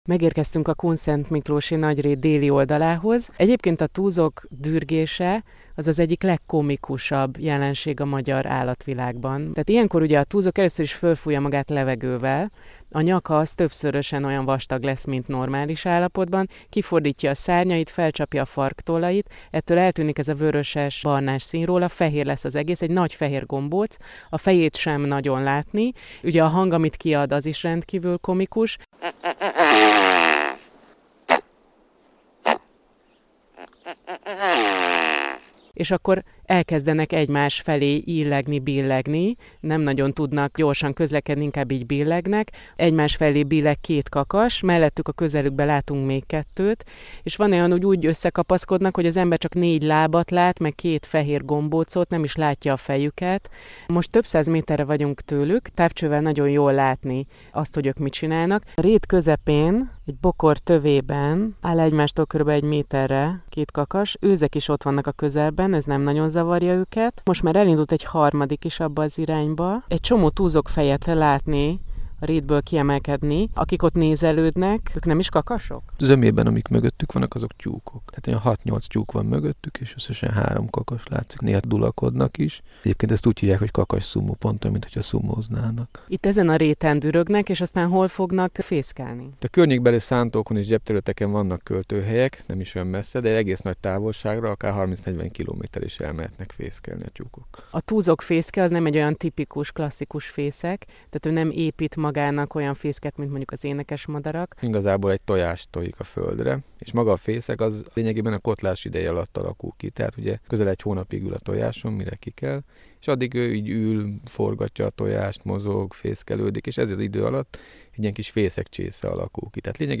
Several Radio Reports